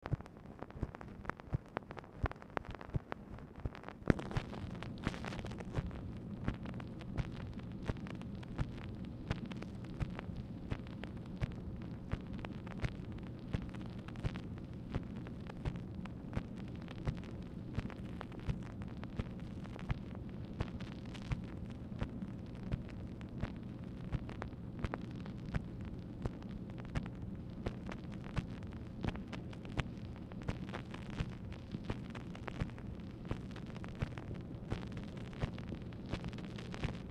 MACHINE NOISE
Format Dictation belt